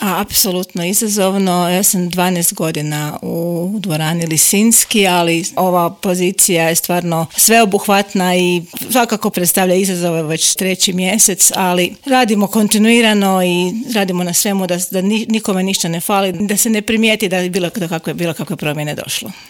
U intervjuu Media servisa rekla je kako je veoma izazovno raditi na toj poziciji.